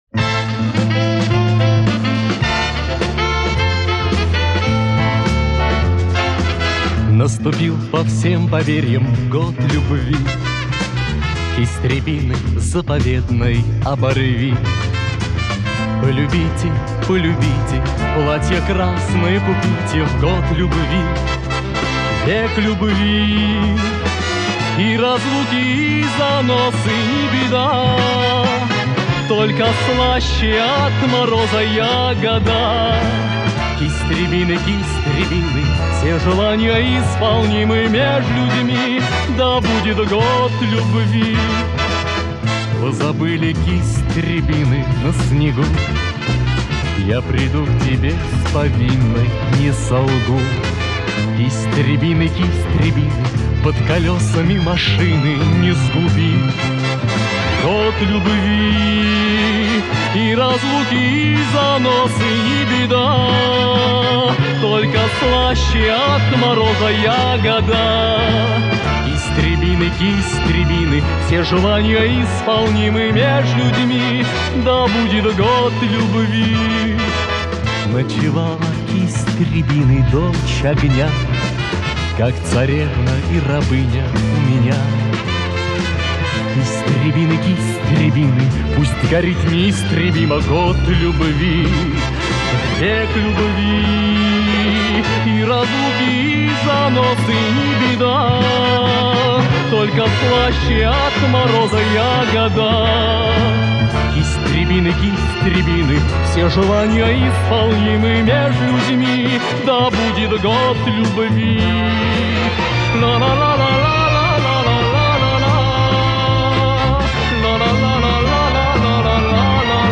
Формат - Пластинки, 7", 33 ⅓ RPM, Mono
Звук отредактирован и восстановлен